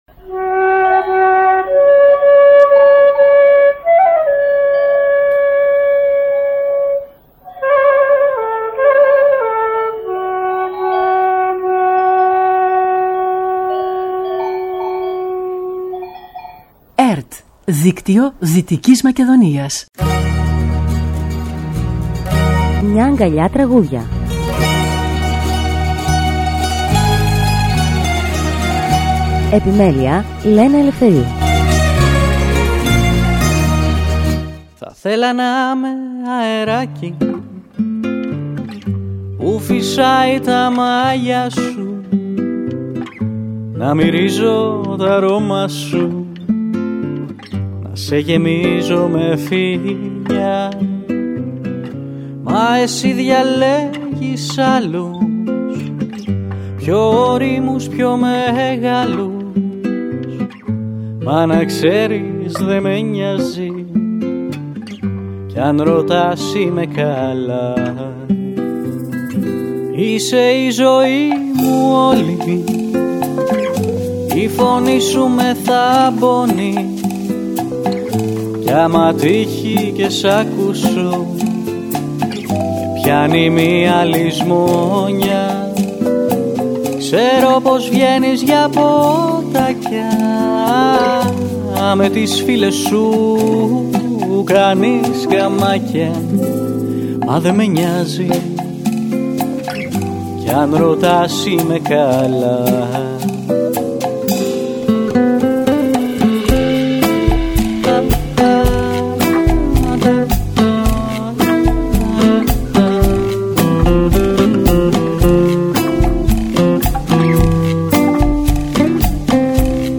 Μουσική εκπομπή που παρουσιάζει νέες δισκογραφικές δουλειές, βιβλιοπαρουσιάσεις και καλλιτεχνικές εκδηλώσεις.
ΣΥΝΕΝΤΕΥΞΗ